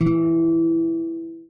guitar_e.ogg